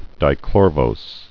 (dī-klôrvōs, -vəs)